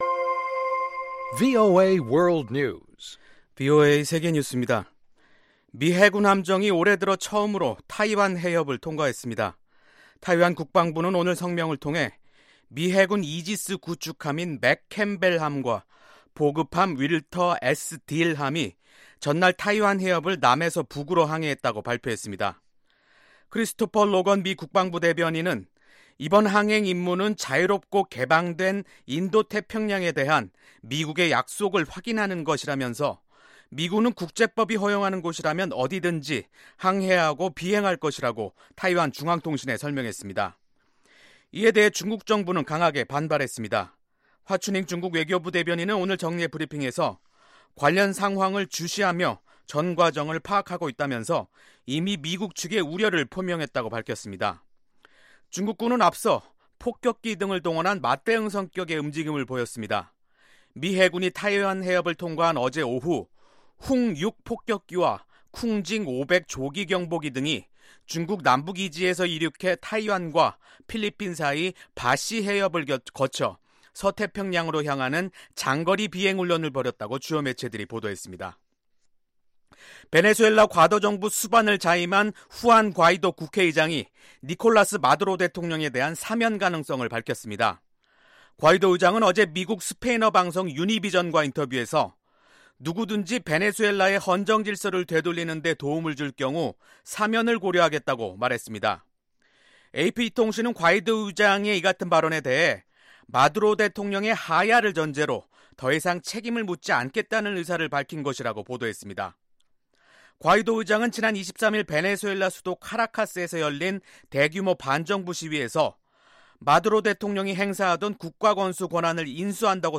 VOA 한국어 간판 뉴스 프로그램 '뉴스 투데이', 2019년 1월 25일 2부 방송입니다. 미-북 협상의 초점이 북한의 핵과 미사일 동결에 맞춰지는 것 아니냐는 관측이 제기되고 있는 가운데, 미국 의원들은 완전한 비핵화가 최종 목표가 돼야 한다고 주장했습니다. 2차 미-북 정상회담에서는 최소한 초기 단계의 실질적 비핵화 조치가 나올 가능성이 있다고 전문가들이 관측했습니다.